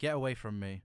Voice Lines / Dismissive
get away from me.wav